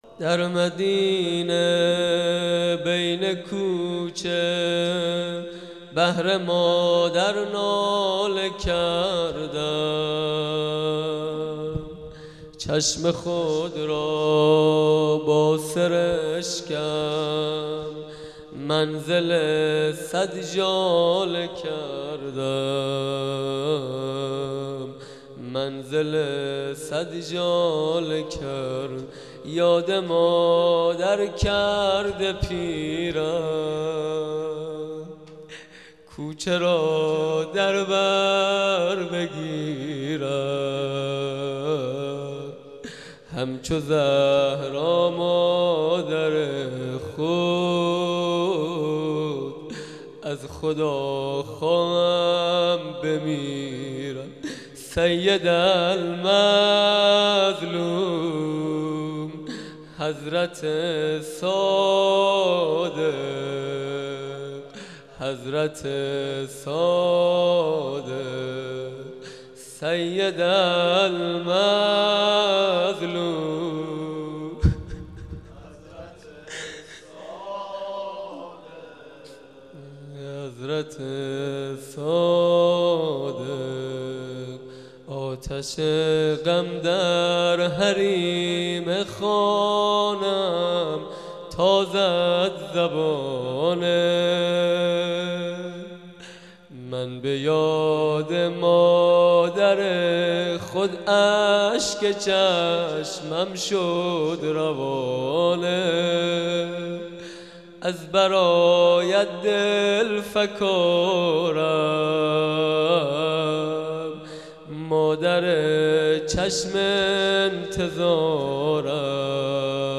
سینه زنی سنگین